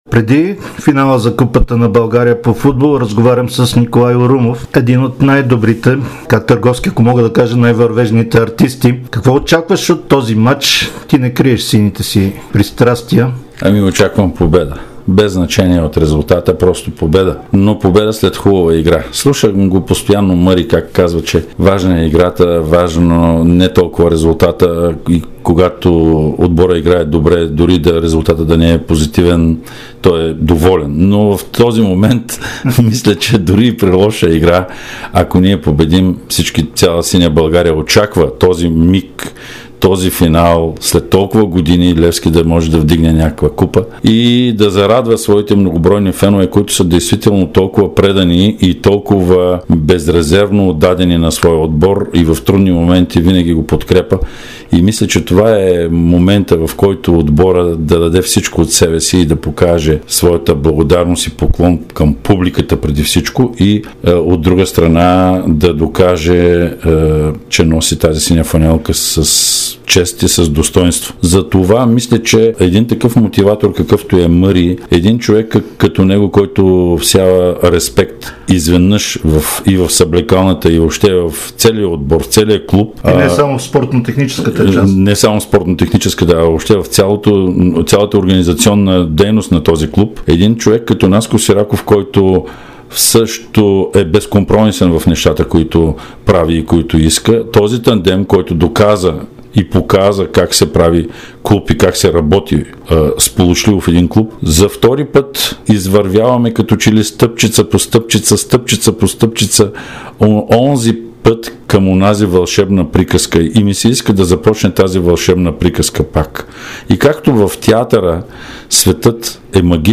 Актьорът и левскар Николай Урумов даде специално интервю за Дарик радио и dsport преди финала на Sesame Купа на България. Според него „магьосниците“ Станимир Стоилов и Наско Сираков могат да поведат „сините“ в „магичен и вълшебен свят“, подобно на приказката от 2006-а година.